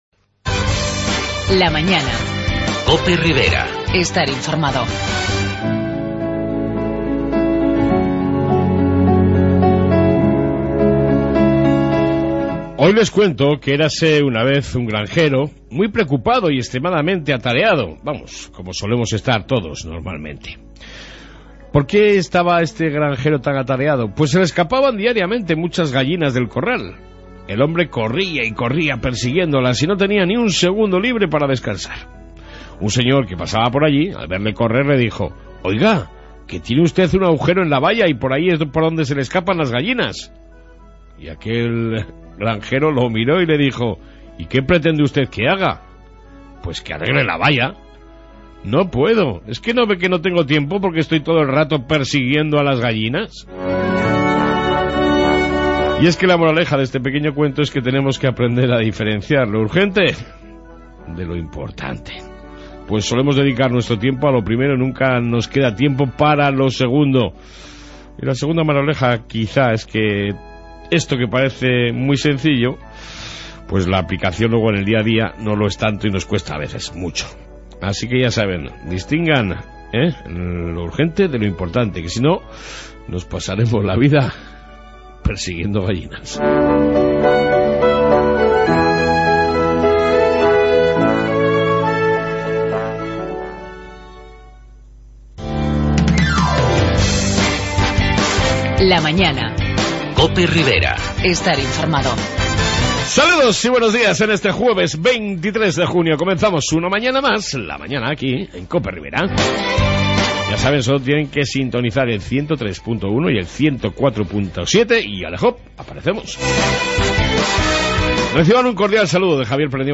AUDIO: En el Programa de Hoy reflexión diaria y amplia entrevista con el candidato de UPN-PP Iñigo Alli.